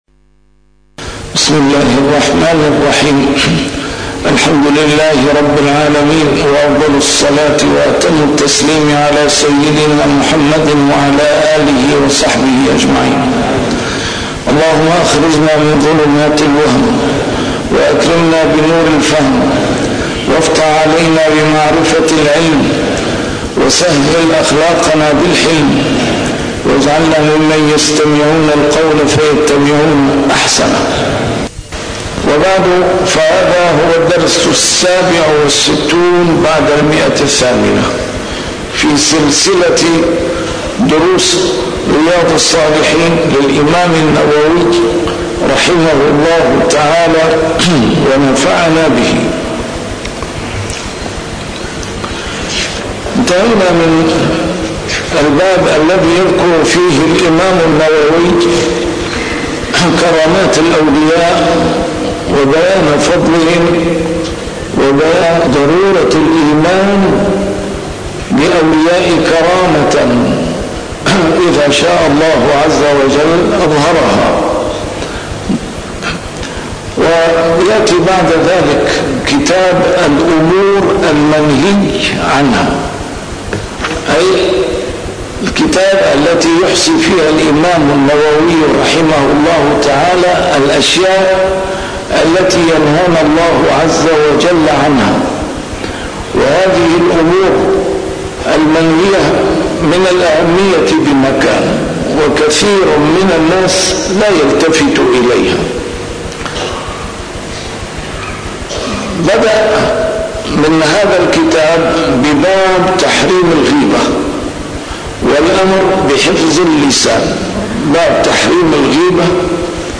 A MARTYR SCHOLAR: IMAM MUHAMMAD SAEED RAMADAN AL-BOUTI - الدروس العلمية - شرح كتاب رياض الصالحين - 867- شرح رياض الصالحين: النهي عن الغيبة